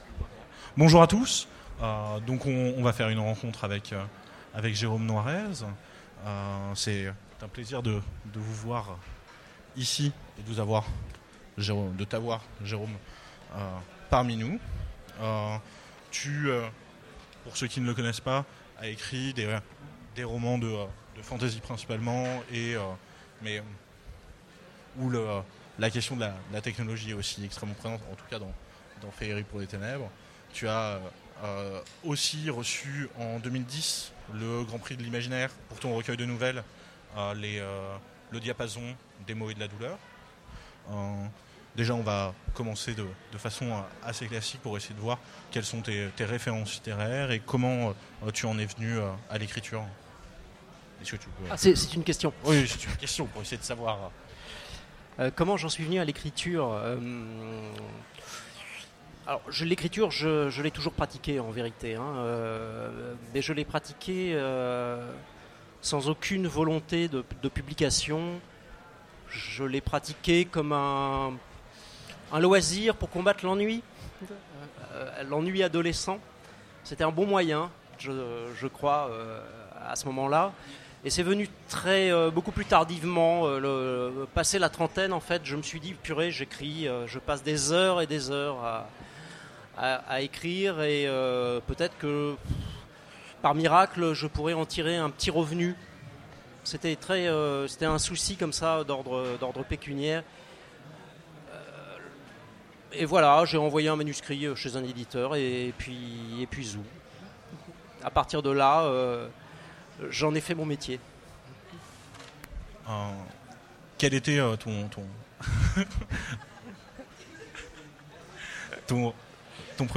Rencontre avec un auteur Conférence